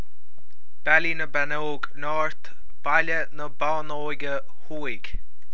Comhad Fuaime Foghraíochta